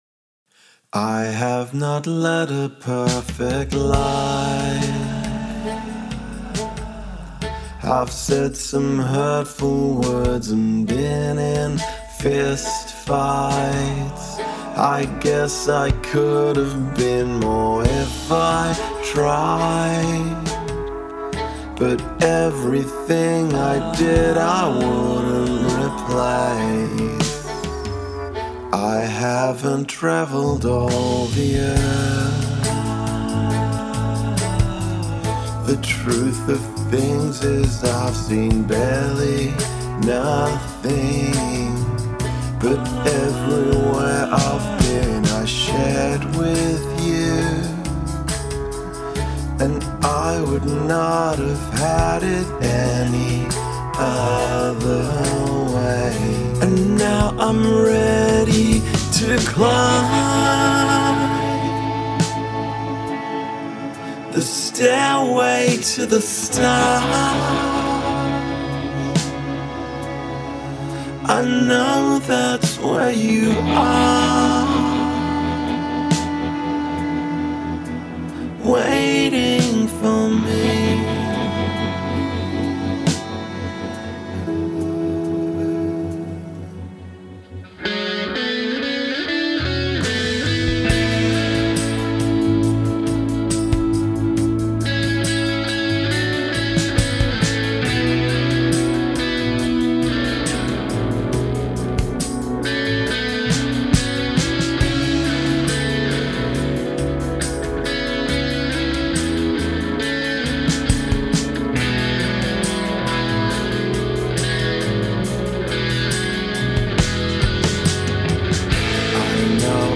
adding violin and recorder